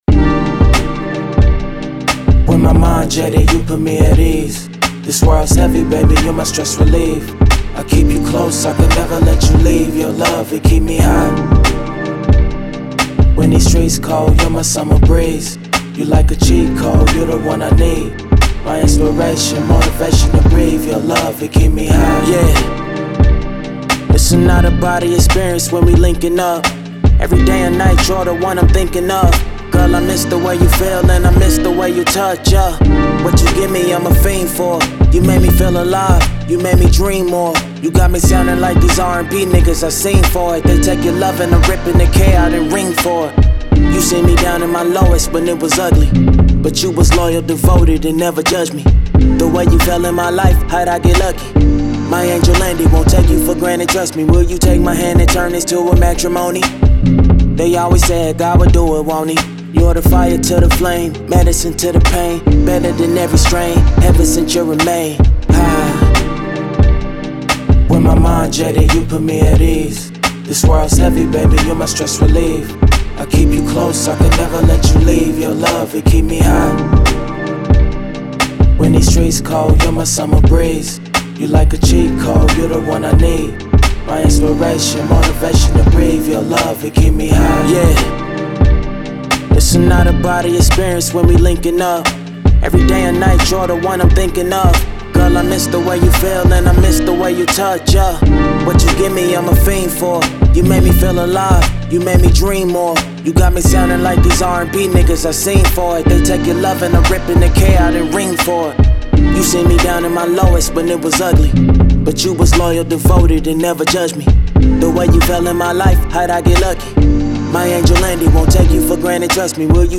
Hip Hop
F major